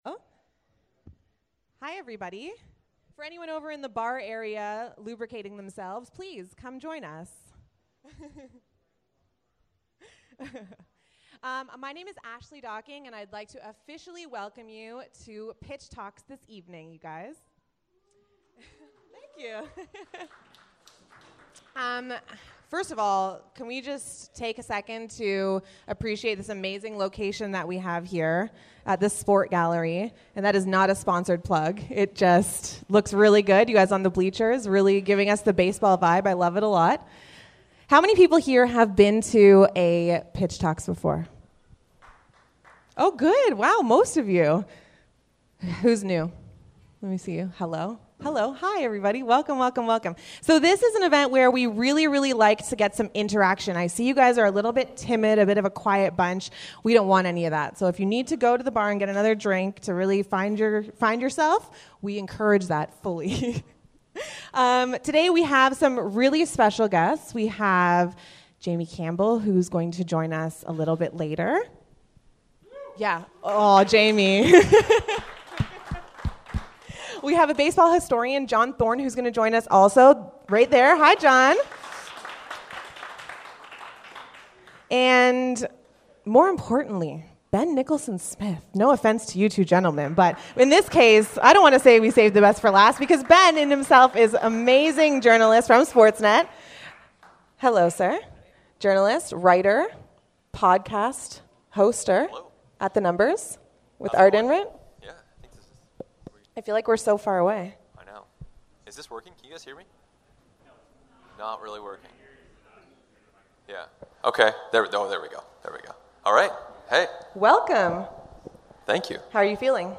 Night One of our Baseball Then & Now Event Panel 1 Features